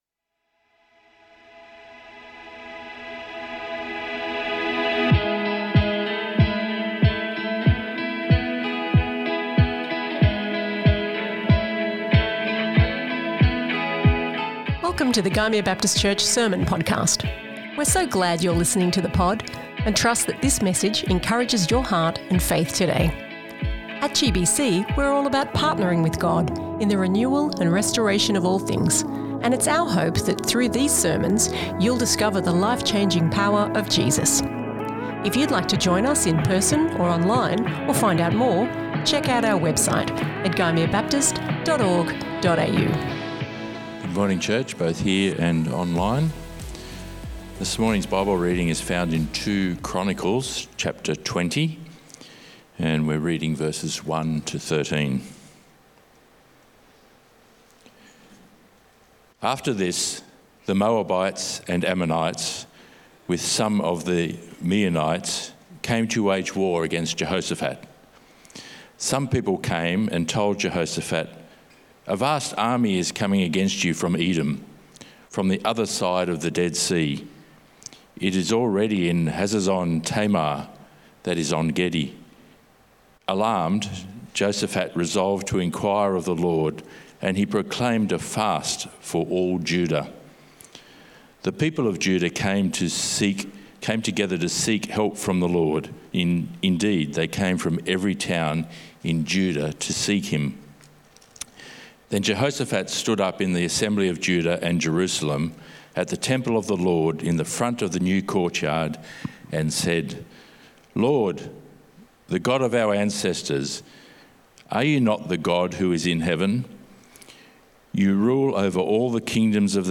GBC | Sermons | Gymea Baptist Church